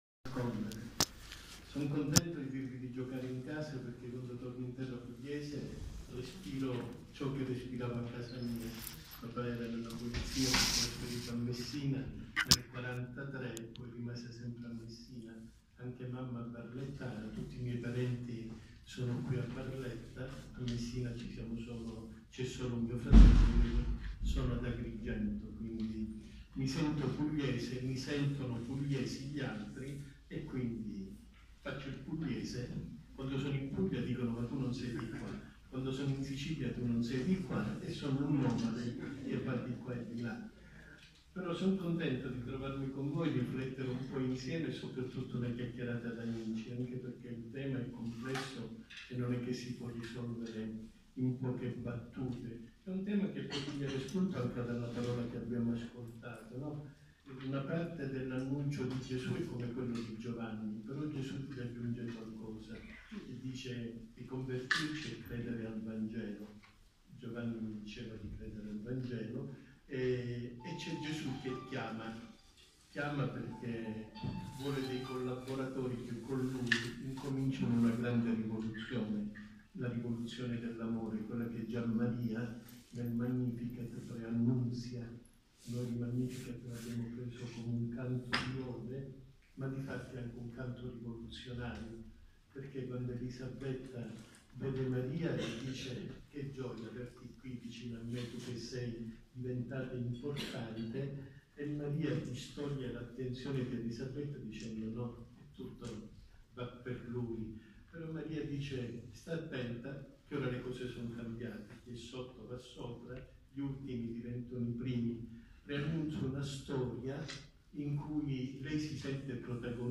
Card. Montenegro, presidente di Caritas italiana, ci ha onorato con la sue presenza….